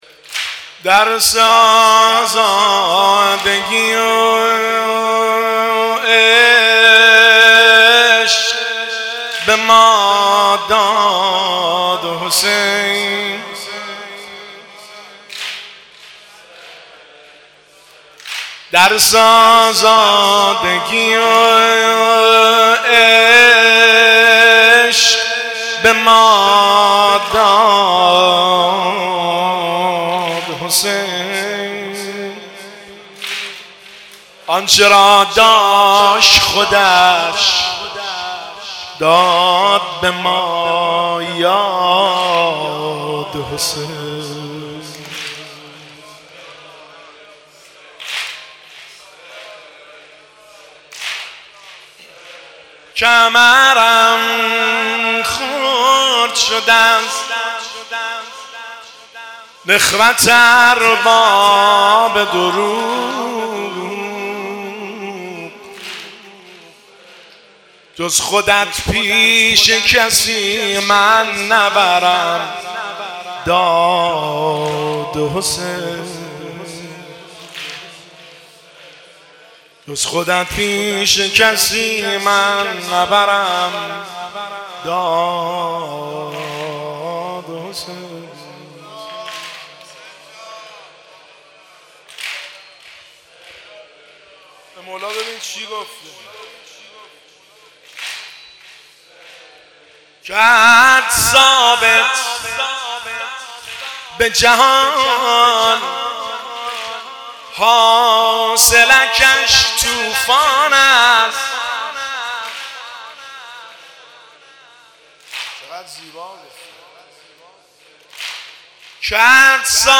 شعرخوانی
هیئت بین الحرمین طهران